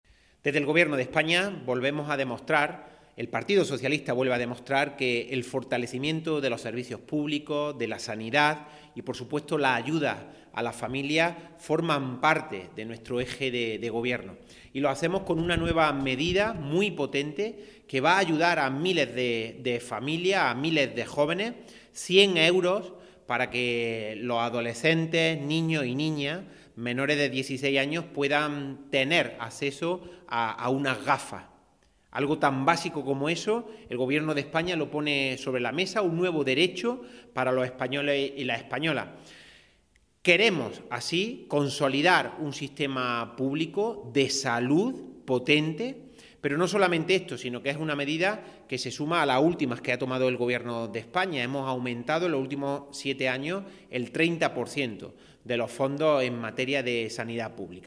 Cortes de sonido # Jose Latorre